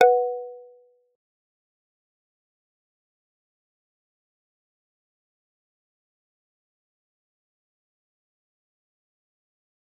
G_Kalimba-B4-mf.wav